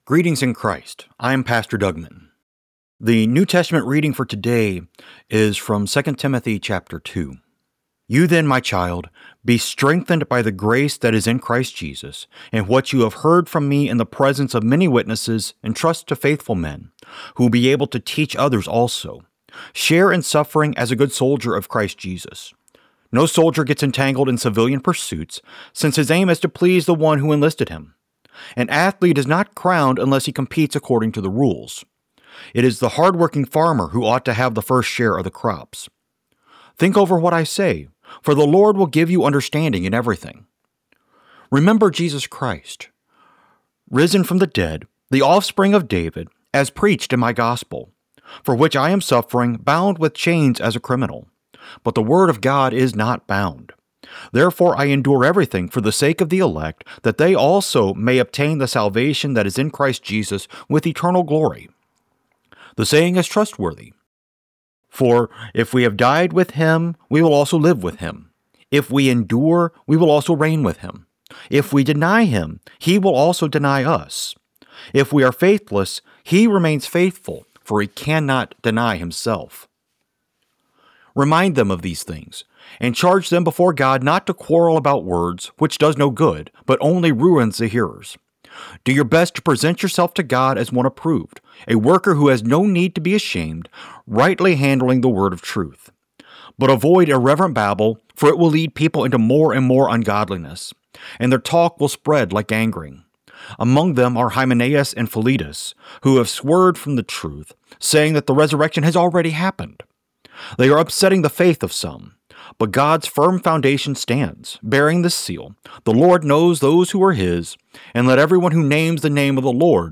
Morning Prayer Sermonette: 2 Timothy 2:1-26
Hear a guest pastor give a short sermonette based on the day’s Daily Lectionary New Testament text during Morning and Evening Prayer.